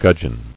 [gudg·eon]